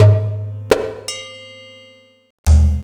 Percussion 06.wav